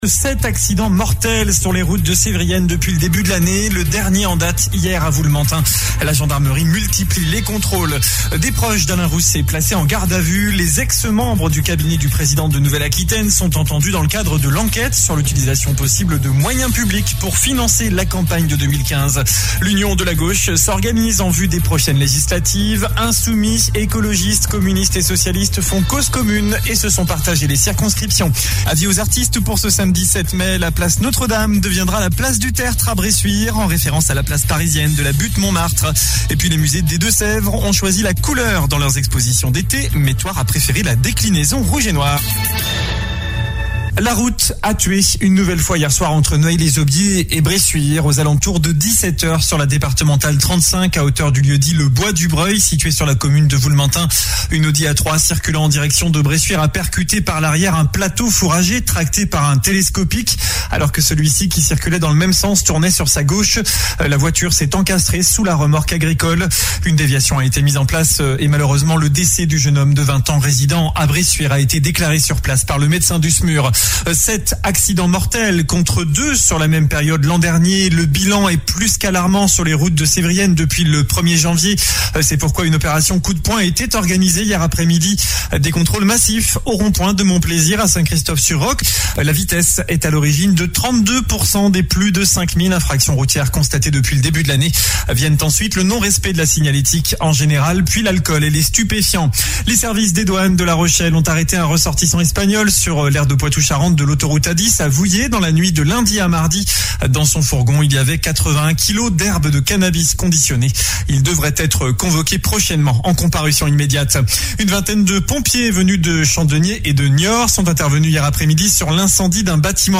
Journal du jeudi 05 mai